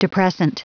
Prononciation du mot depressant en anglais (fichier audio)
Prononciation du mot : depressant
depressant.wav